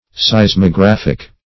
Search Result for " seismographic" : The Collaborative International Dictionary of English v.0.48: Seismographic \Seis`mo*graph"ic\, a. Of or pertaining to a seismograph; indicated by a seismograph.
seismographic.mp3